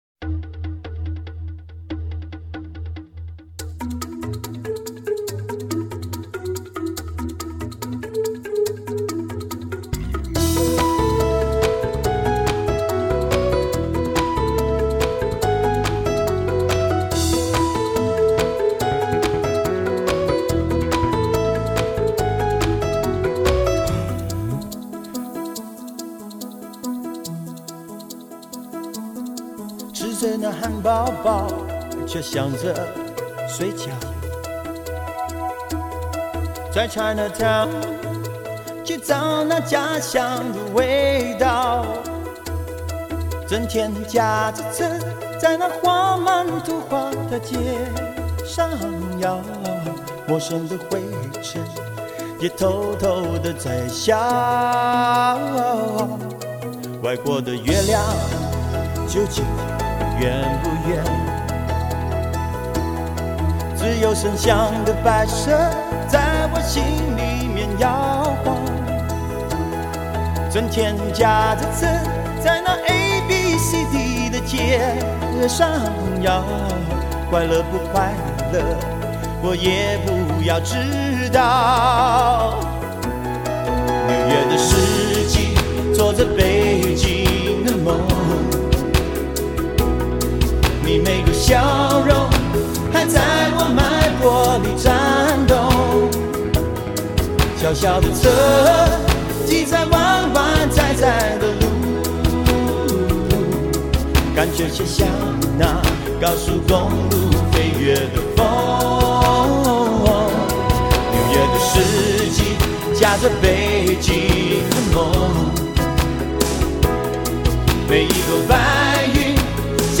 K2HD Mastering
将100KHz宽频带/24bit音频信息载入
音色更接近模拟(Analogue)声效
强劲动态音效中横溢出细致韵味